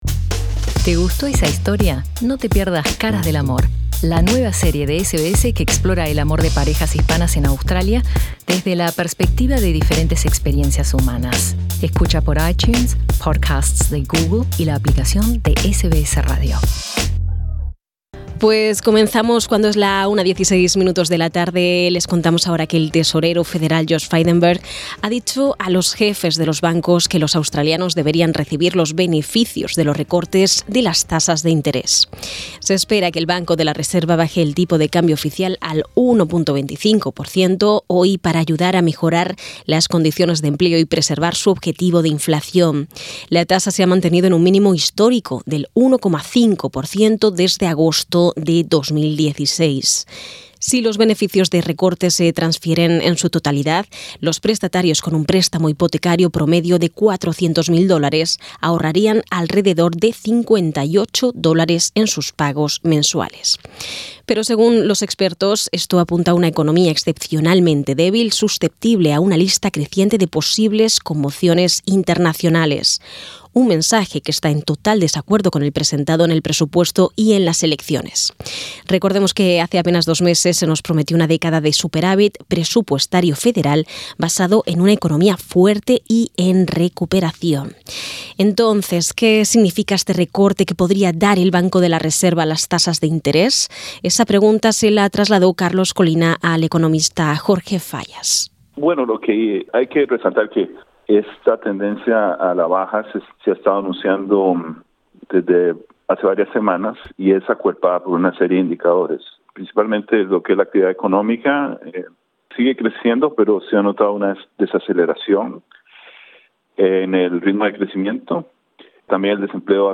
Escucha la entrevista que se realizó antes del anuncio.